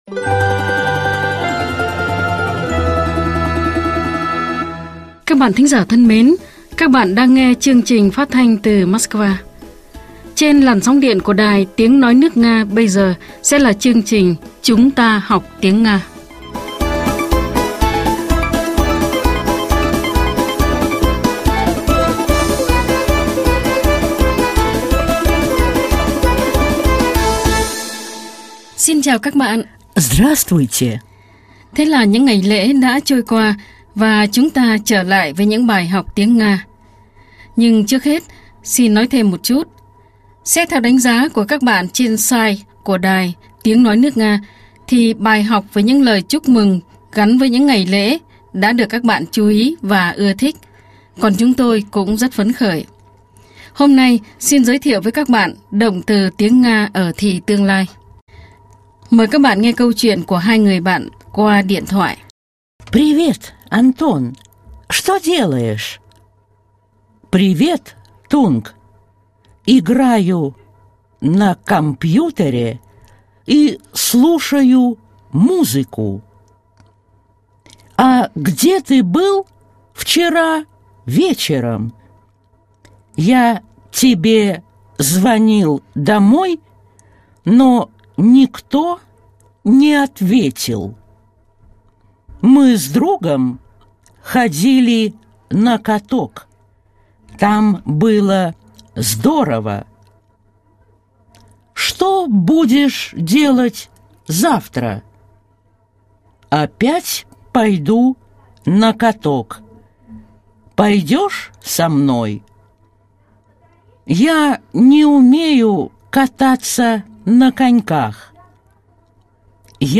Bài 29 – Bài giảng tiếng Nga - Tiếng Nga cho mọi người
Hôm nay chúng tôi sẽ giới thiệu cách chia động từ tiếng Nga ở thì quá khứ, hiện tại và tương lai. Mời các bạn nghe câu chuyện của hai người bạn qua điện thoại: ПРИВЕТ, АНТОН!